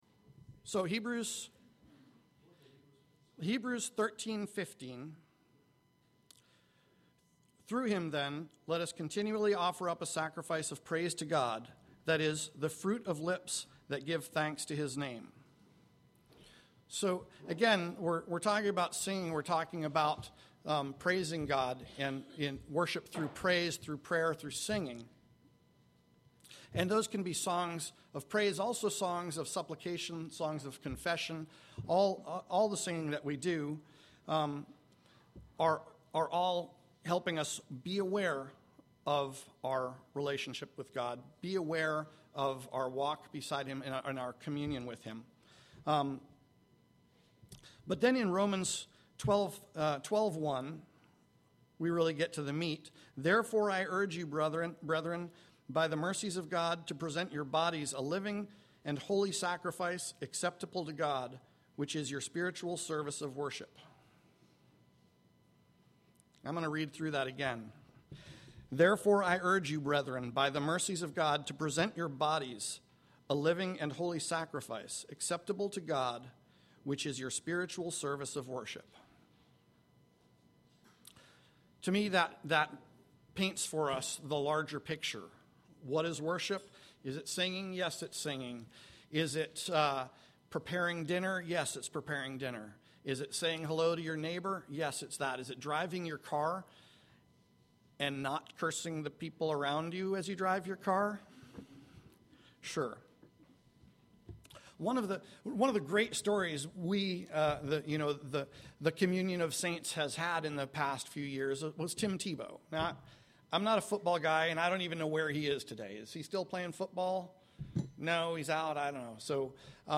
Editor’s note: Unfortunately, we had some technical difficulties partway through the sermon, and the first part of the sermon was deleted.